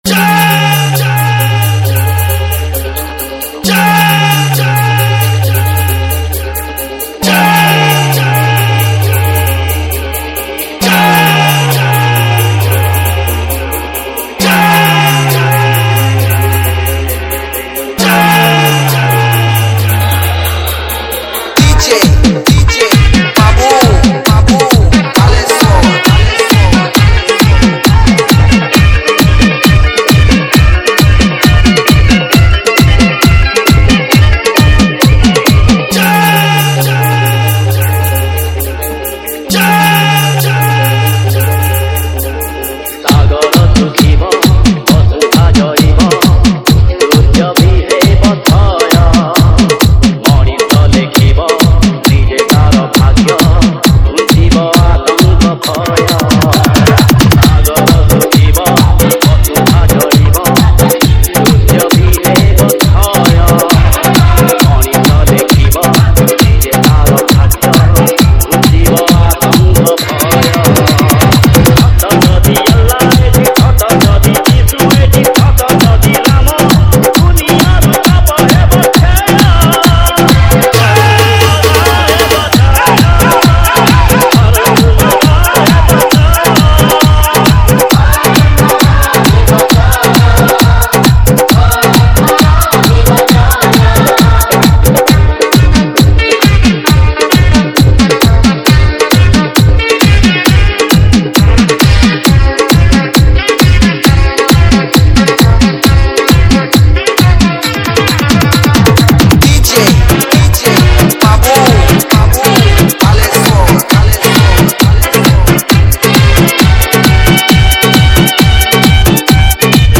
ODIA BHAJAN DJ REMIX